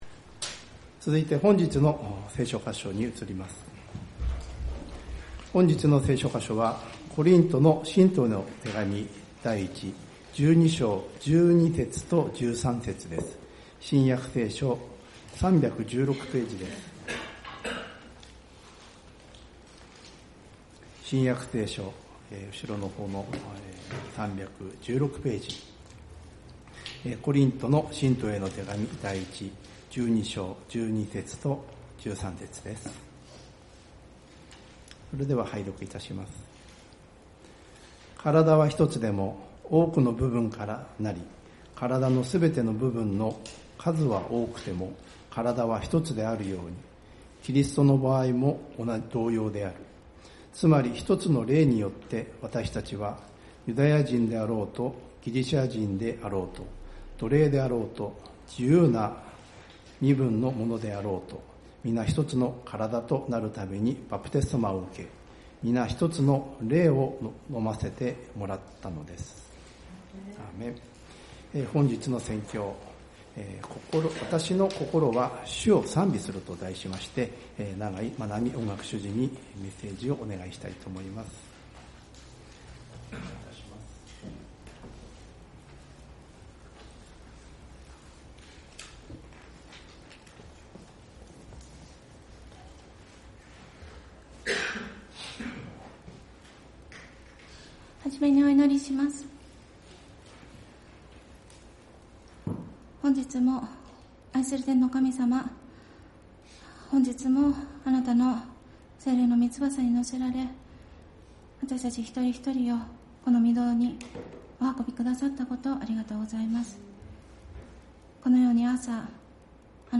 聖日礼拝「私の心は主を賛美する」